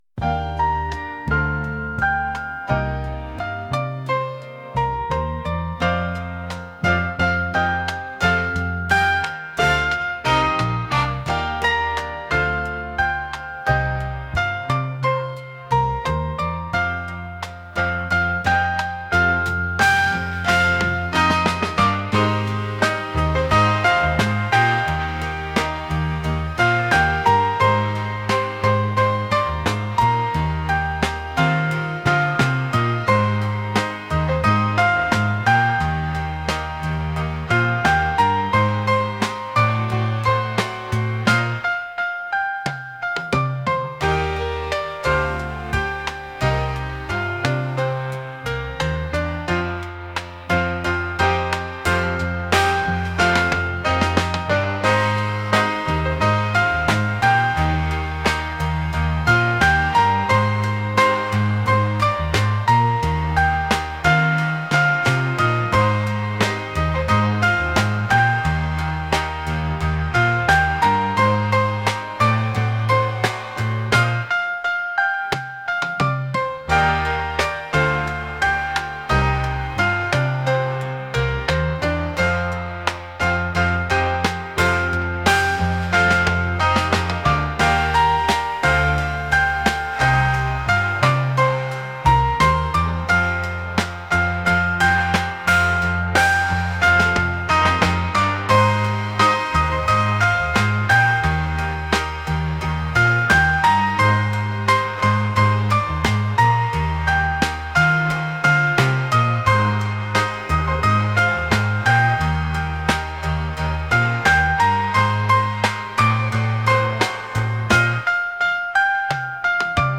soul | smooth